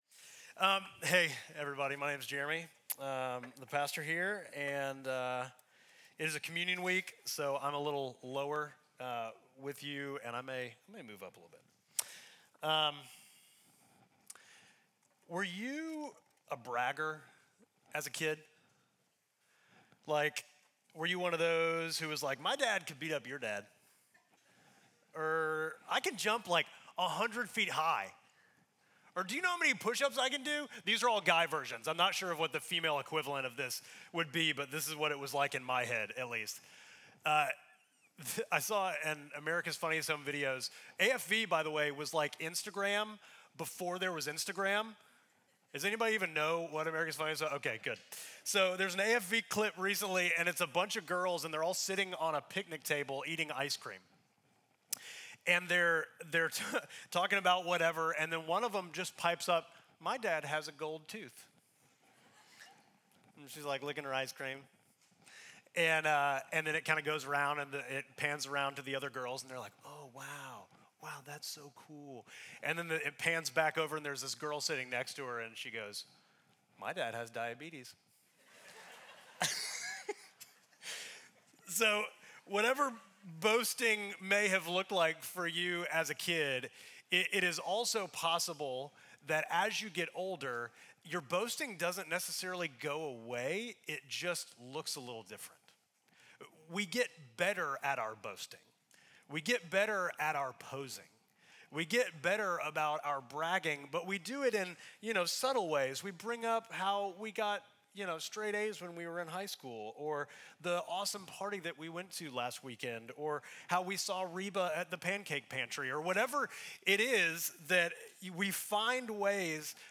Midtown Fellowship Crieve Hall Sermons Lactose Intolerance Feb 02 2025 | 00:41:19 1x 00:00 / 00:41:19 Subscribe Share Apple Podcasts Spotify Overcast RSS Feed Share Link Embed